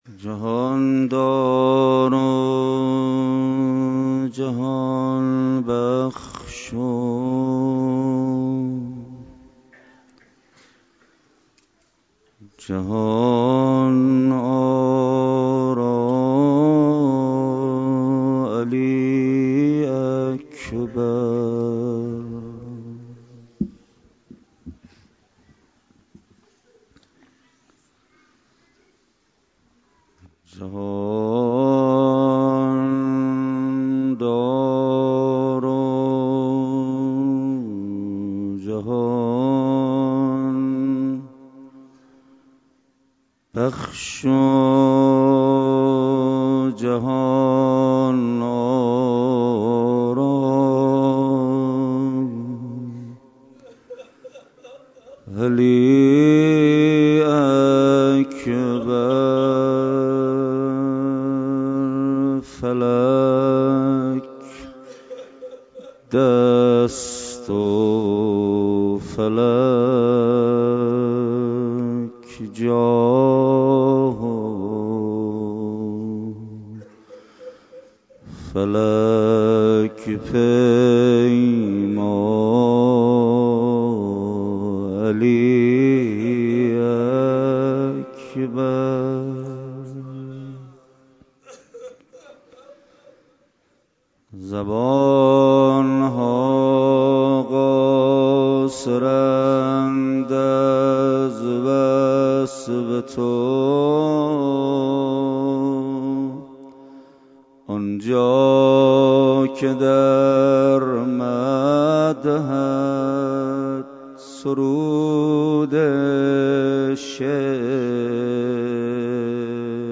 روضه حضرت علی اکبر علیه السلام